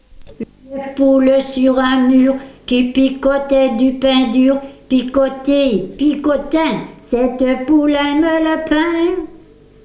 Genre : chant
Type : comptine, formulette
Province d'origine : Hainaut
Lieu d'enregistrement : Lessines
Support : bande magnétique